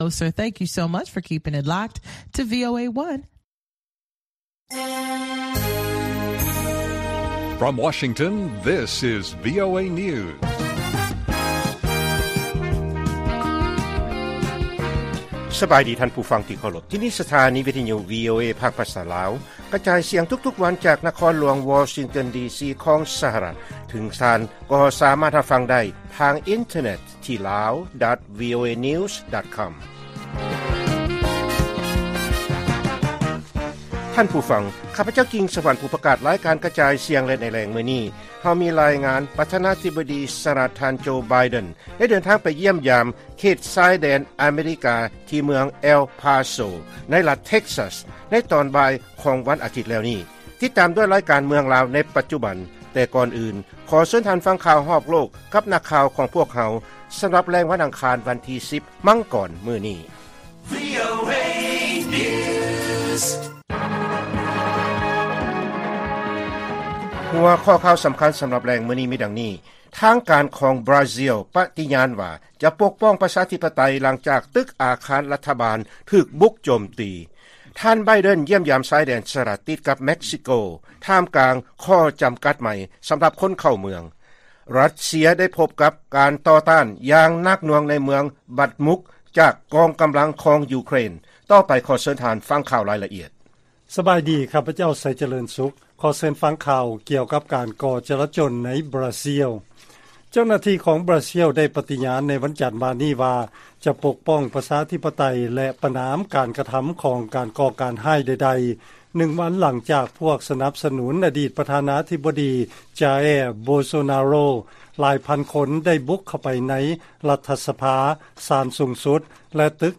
ລາຍການກະຈາຍສຽງຂອງວີໂອເອ ລາວ: ທາງການຂອງບຣາຊີລ ປະຕິຍານວ່າ ຈະປົກປ້ອງປະຊາທິປະໄຕ ຫຼັງຈາກການໂຈມຕີຕໍ່ຕຶກອາຄານຂອງລັດຖະບານ